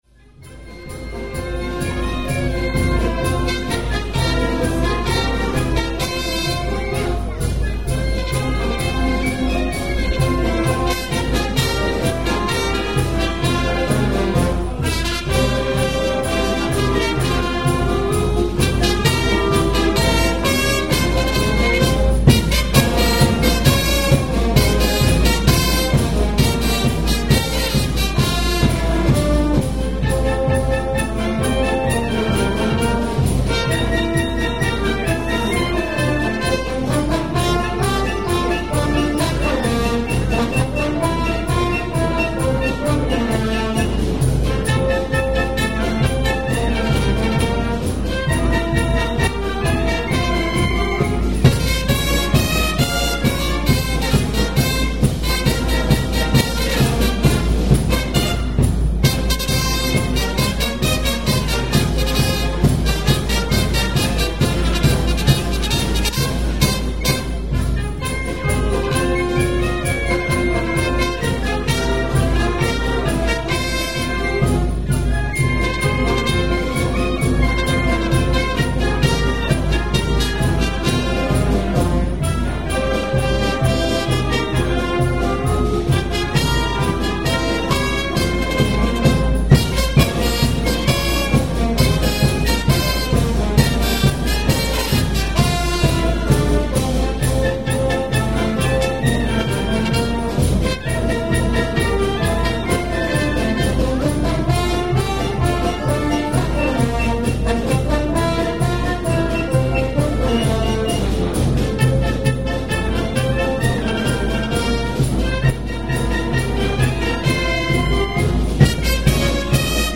13th April and the Band plays
This Sunday to celebrate the Quarantore, the Barga Band where playing live in the Piazza to keep the visitors entertained.